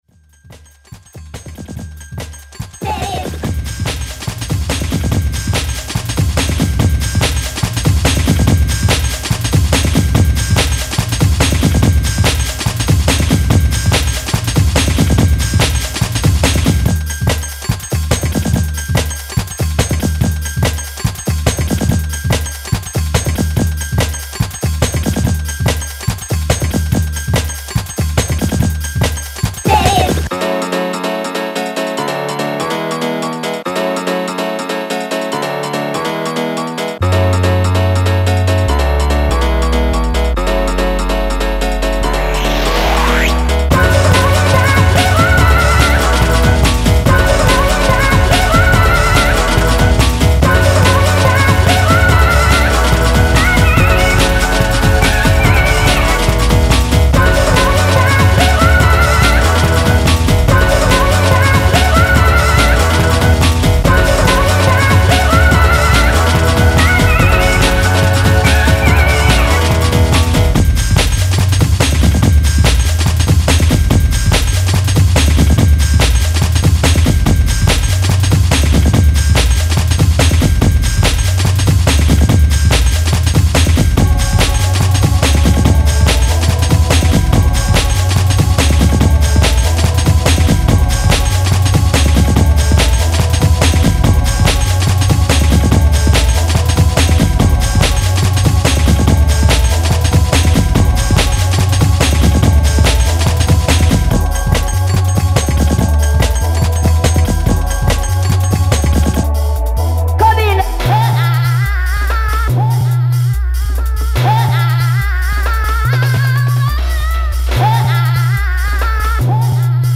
high-energy, sample-laden tracks